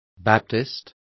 Complete with pronunciation of the translation of baptist.